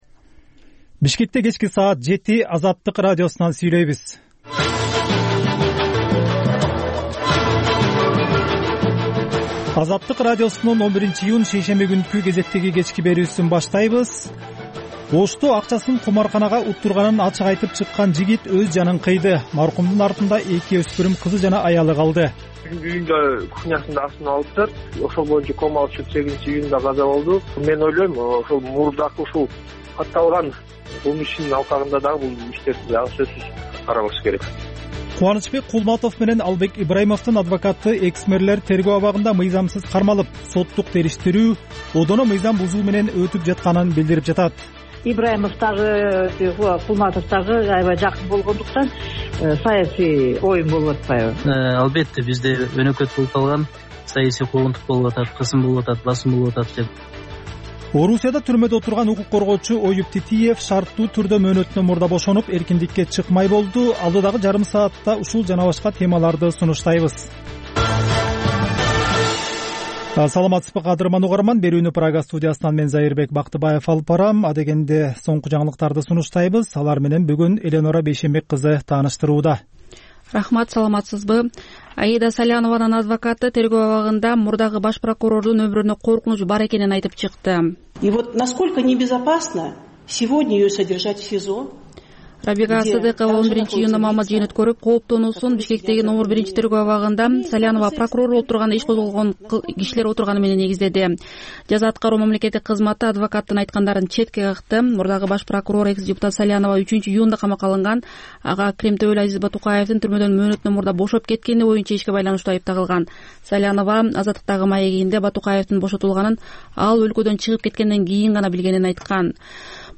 Бул үналгы берүү ар күнү Бишкек убакыты боюнча саат 19:00дөн 20:00гө чейин обого түз чыгат.